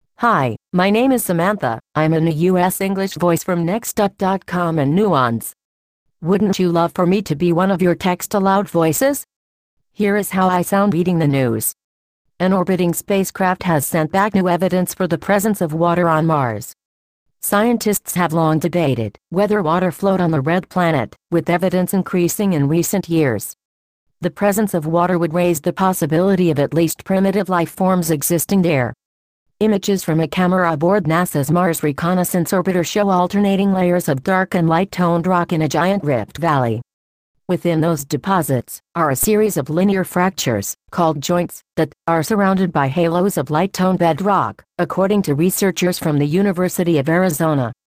Texte de d�monstration lu par Samantha (Nuance RealSpeak; distribu� sur le site de Nextup Technology; femme; anglais)